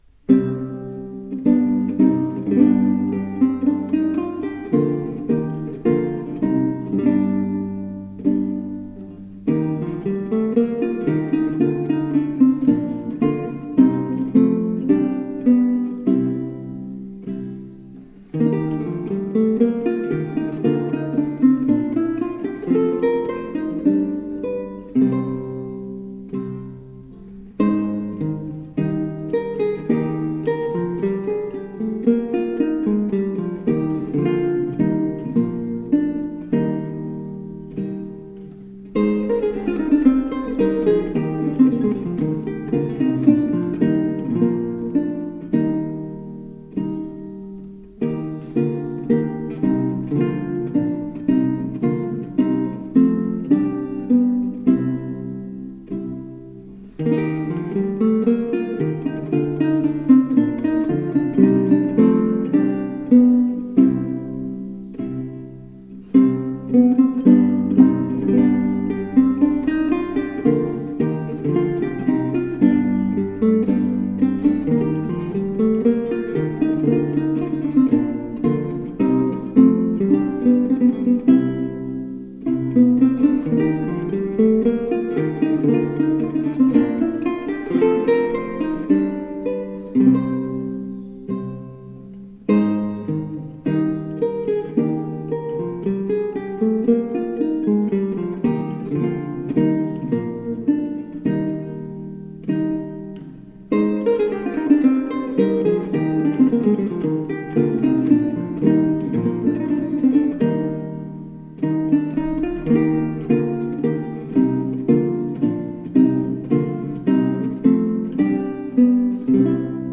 Renaissance songs.